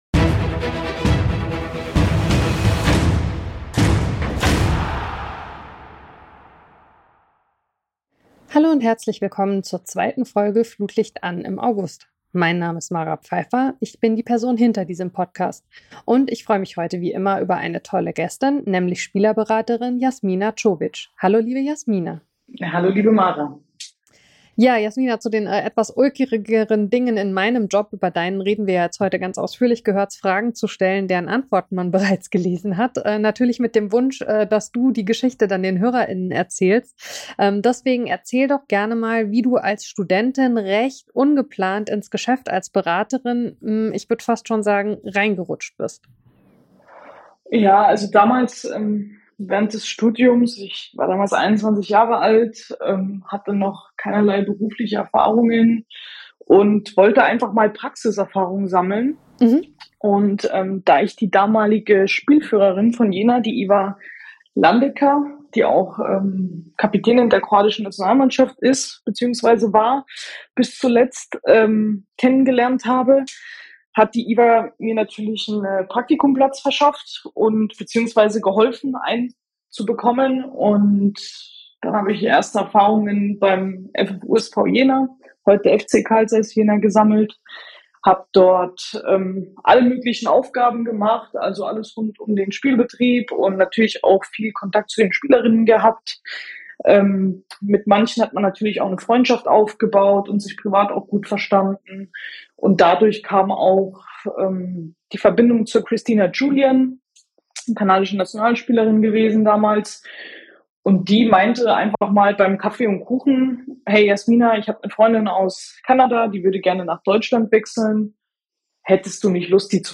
Die Spielerberaterin spricht über Widerstände, Förderer & erklärt, was sich im Fußball ändern muss.